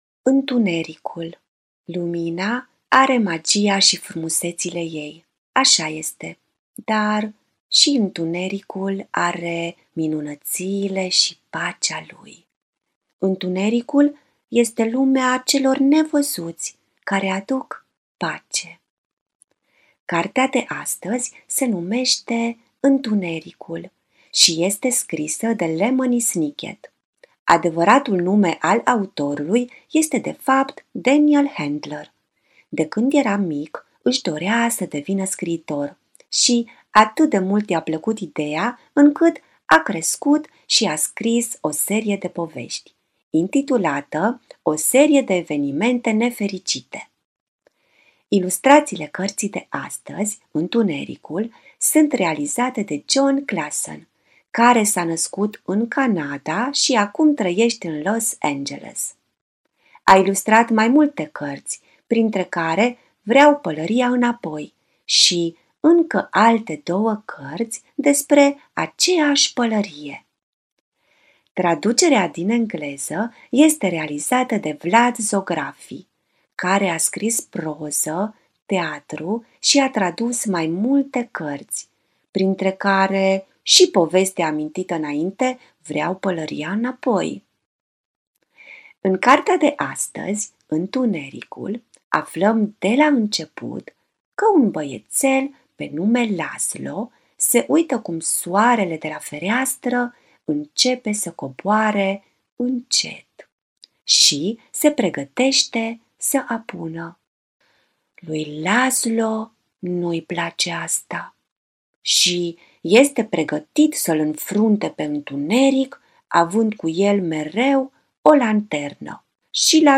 artist păpușar